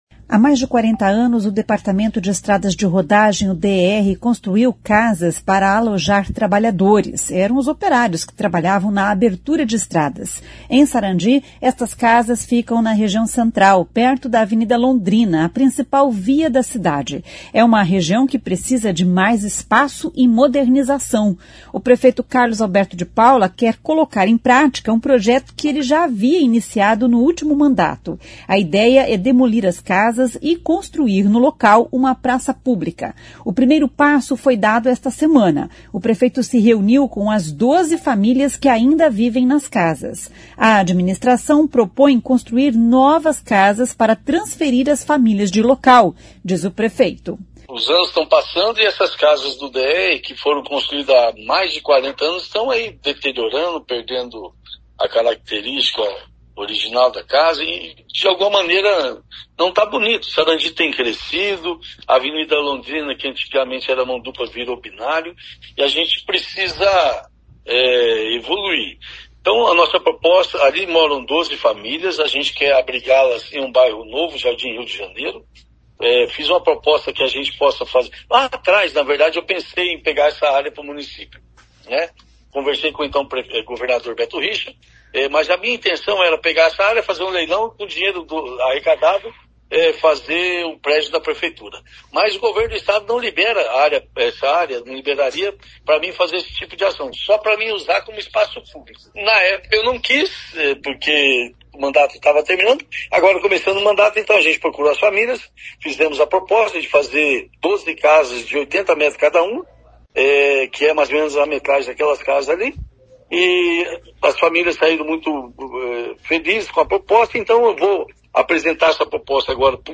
A administração propõe construir novas casas para transferir as famílias de local, diz o prefeito.